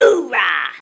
hoorah